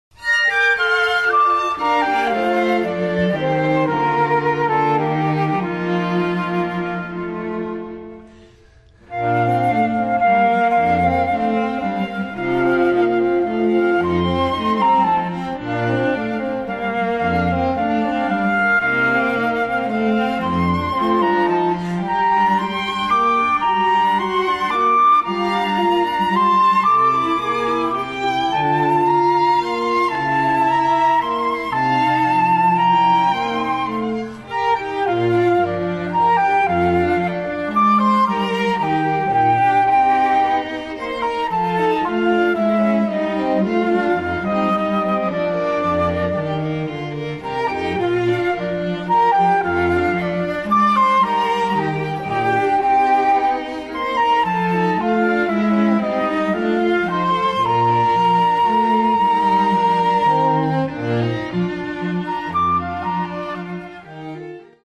FLUTE QUARTET
(Flute, Violin, Viola and Cello)
MIDI